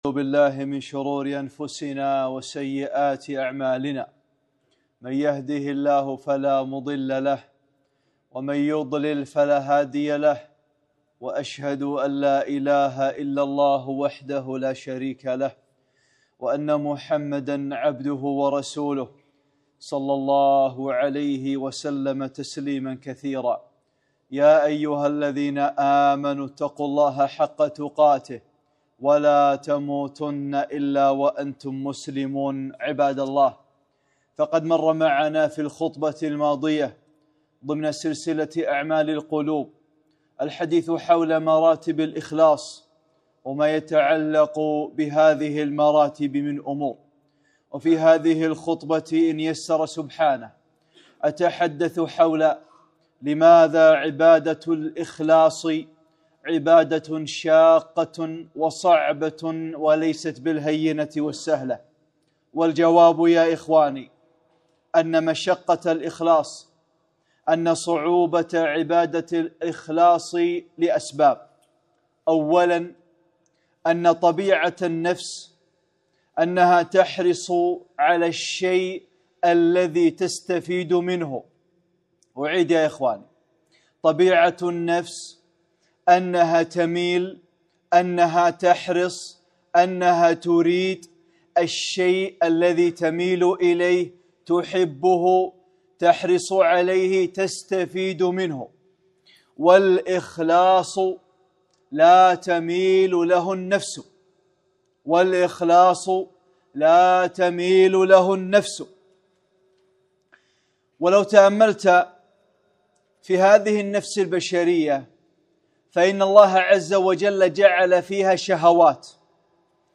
(12) خطبة - لماذا الإخلاص شاق وصعب؟